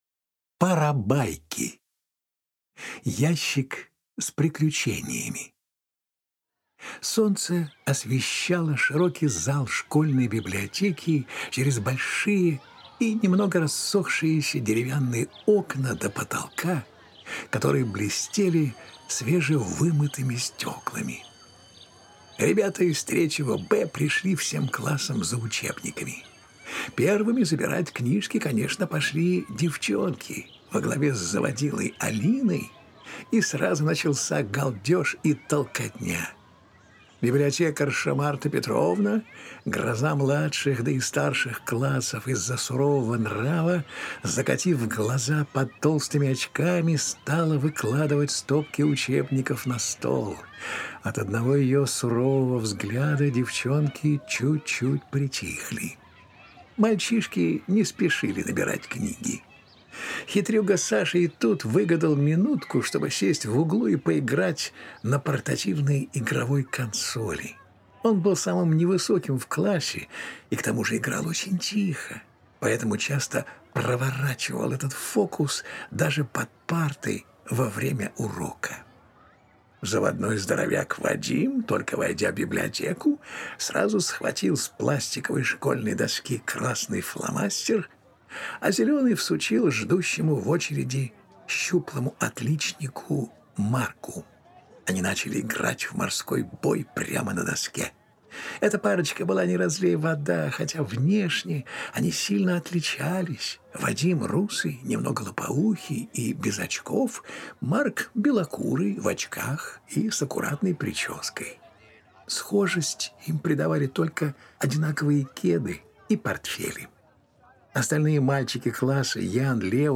Аудиосериал создаёт эффект полного погружения и дарит ощущение настоящего сказочного приключения.